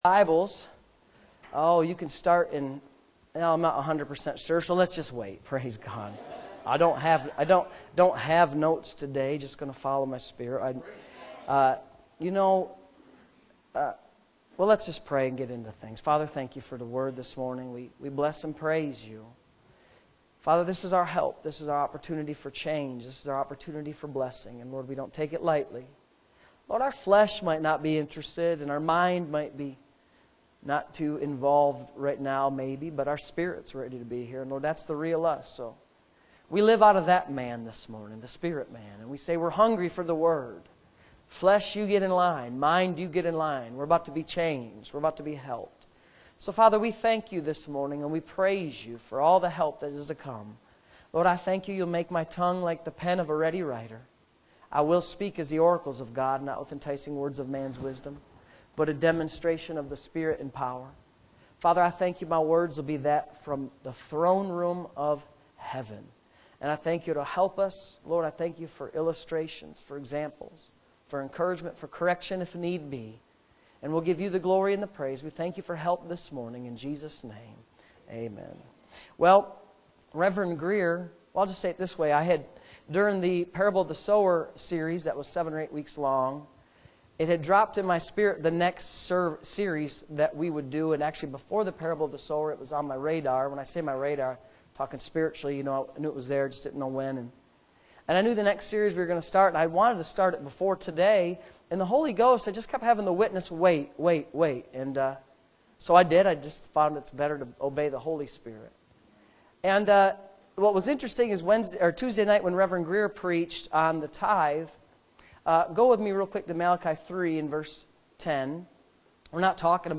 Sunday Morning Services